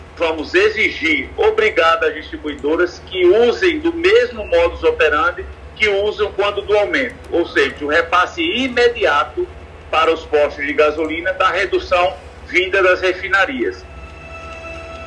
Em entrevista ao programa Arapuan Verdade, da Rádio Arapuan FM desta quarta-feira, o secretário do Procon-JP, Rougger Guerra, afirmou que irá cobrar dos postos a redução imediata do preço praticado ao consumidor final.